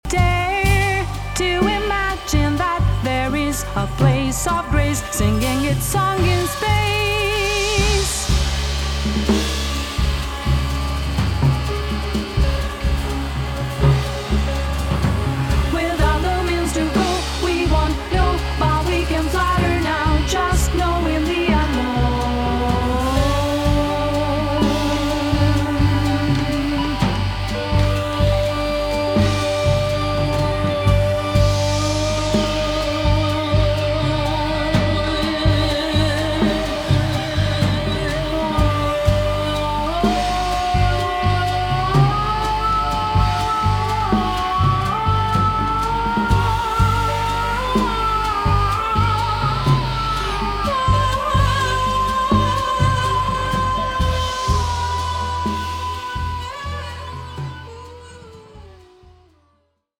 singer and composer
lush instrumentation and dreamy choirs
Soul Funk Jazz